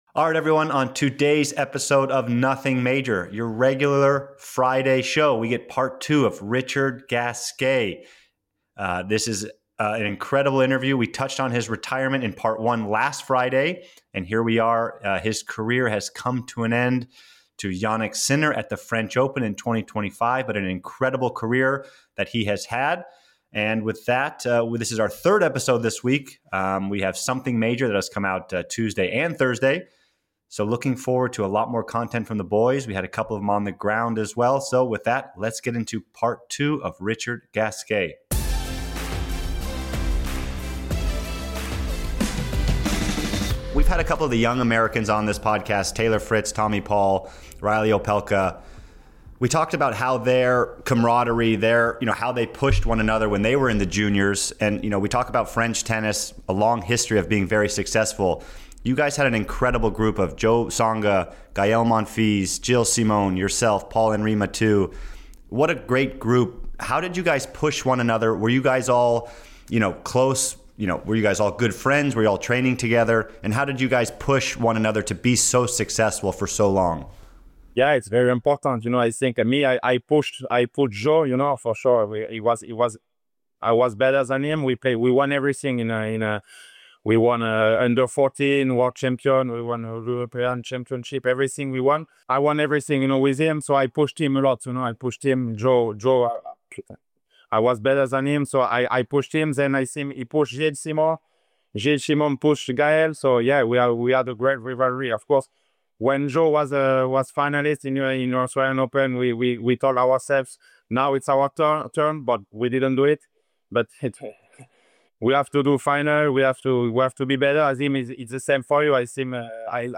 In part two of our special interview with former World No. 7 Richard Gasquet, the French legend dives deeper into his career with stories, insights, and honesty only a recently retired pro can offer.